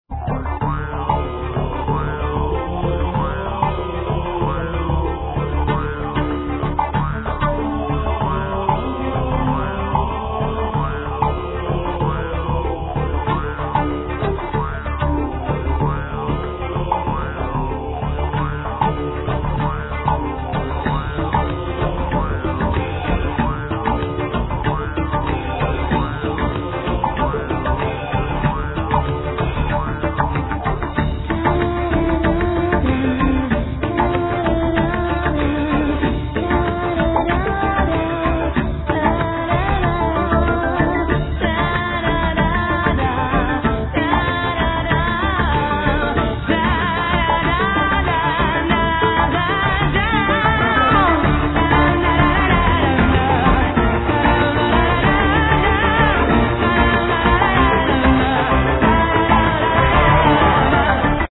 Vocals, Pipes
Flute, Pipe, 7-hole flute, Tambourine, Vocals
Acoustic guitar
Tarogato (Oboe-like shawm)
Doromb (mouth harp)